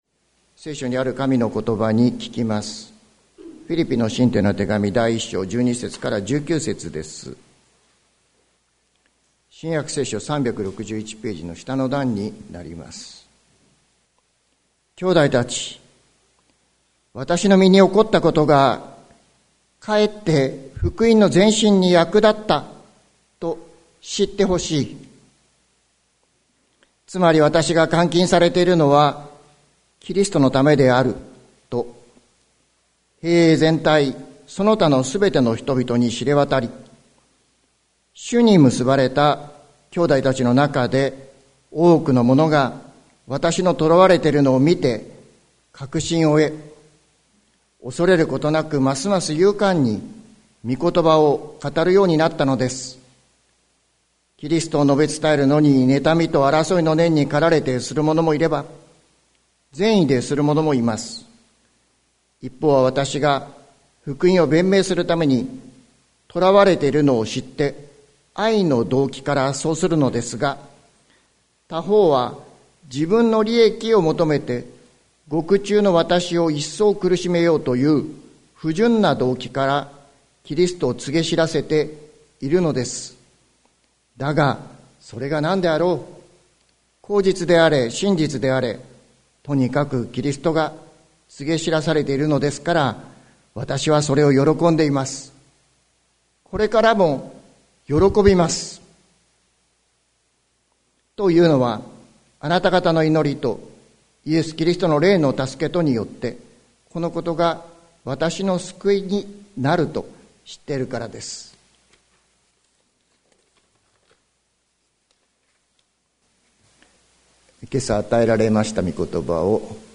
2024年04月21日朝の礼拝「喜びの秘訣」関キリスト教会
説教アーカイブ。